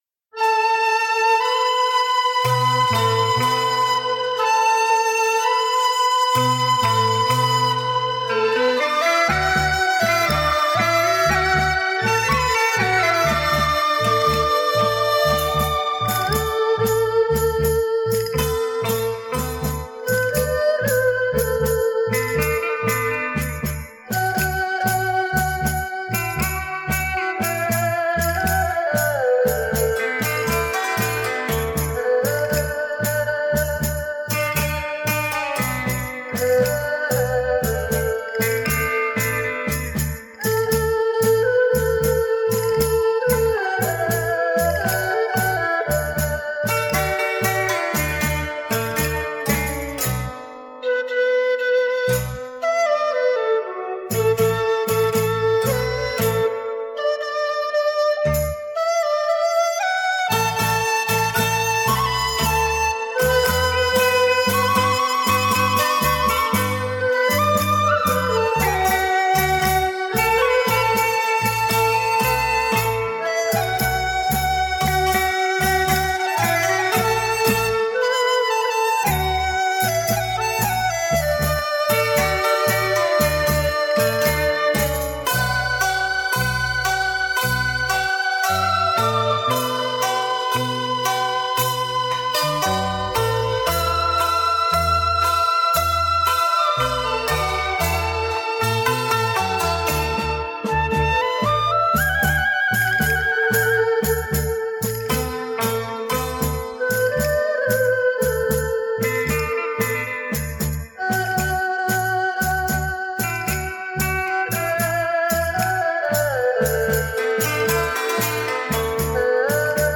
演奏乐器：二胡 笛子 扬琴 洞箫 吉他 电子琴等等